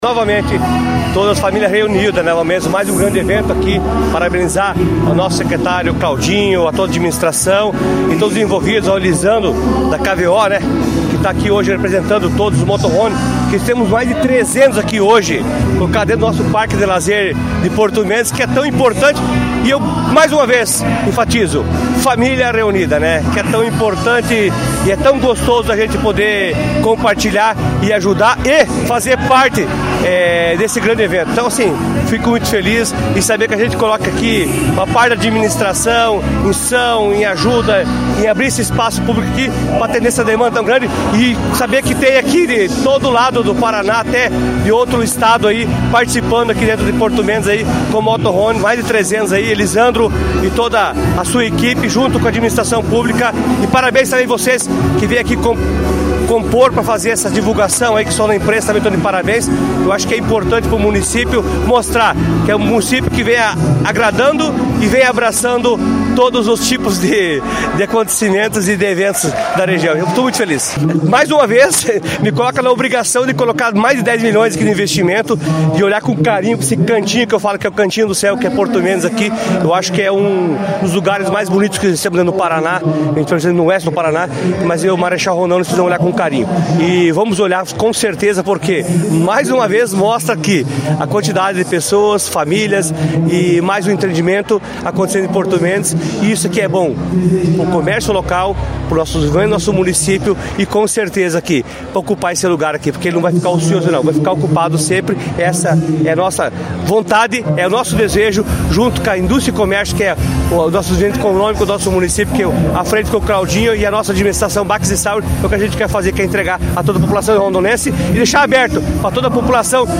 O prefeito Adriano Backes comenta sobre mais este evento com o propósito de alavancar o turismo no distrito rondonense….